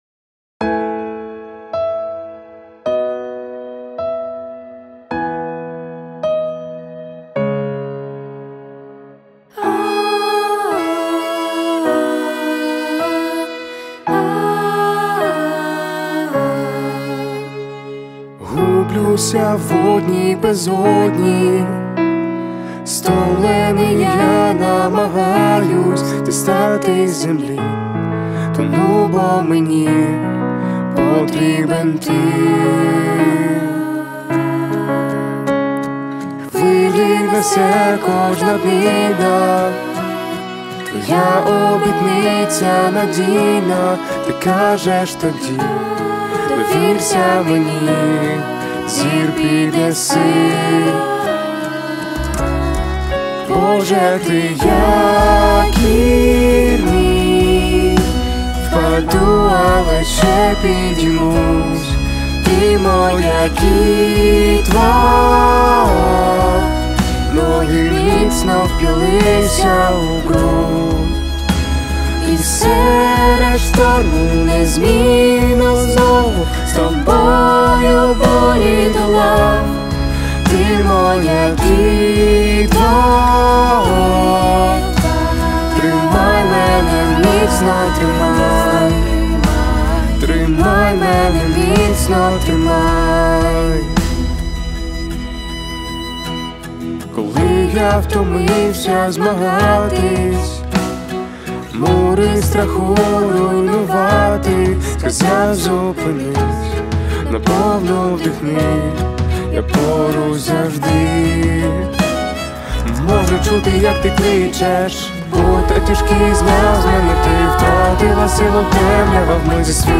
песня
612 просмотров 193 прослушивания 18 скачиваний BPM: 160